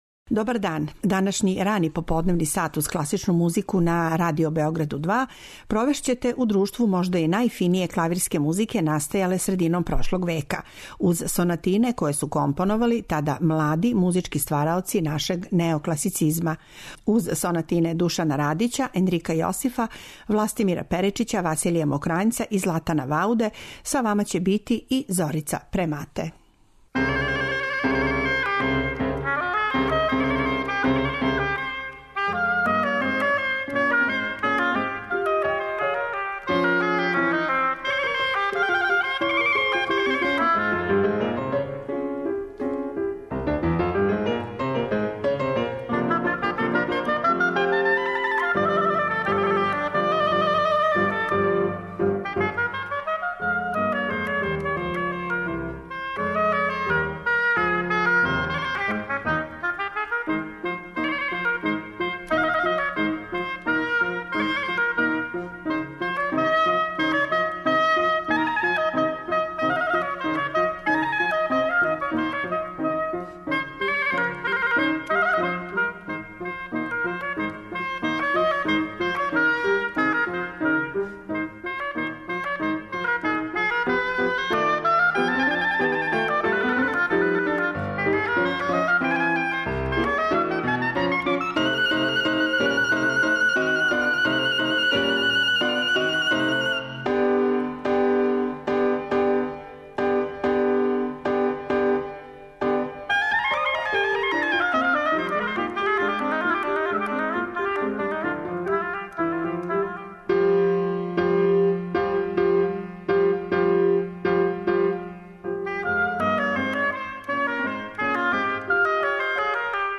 Емитоваћермо већином ретке архивске снимке остварене пре неколико деценија, с обзиром на то да се ова музика пуна оригиналности и изврсних композиционо-техничких идеја данас веома ретко изводи и снима.